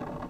Rumble.wav